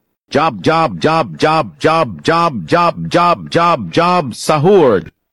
Sound Effects
Sad Violin (the Meme One)